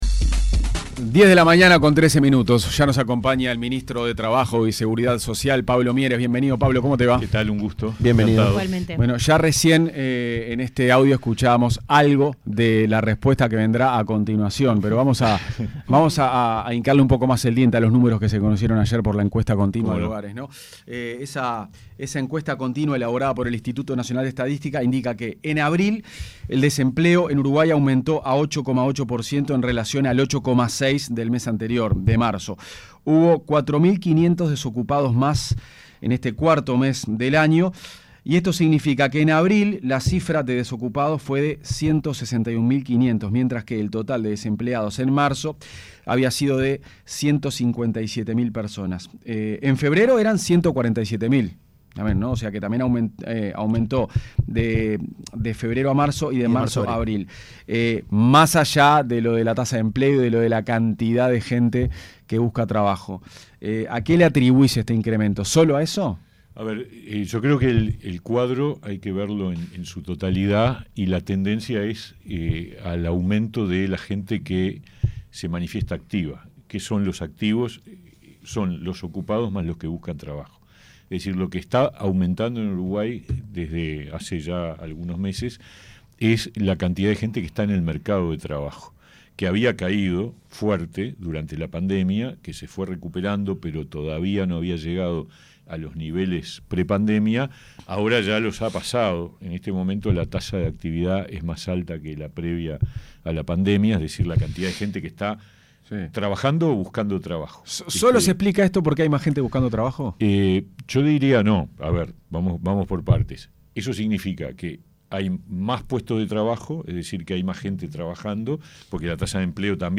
El ministro de Trabajo y Seguridad Social, Pablo Mieres, fue consultado en Punto de Encuentro por los dichos del intendente de Canelones, Yamandú Orsi, que afirmó que al gobierno le preocupa más quién se atiende en el Hospital policial que la cantidad de homicidios.
Entrevista-al-MTSS-Pablo-Mieres.mp3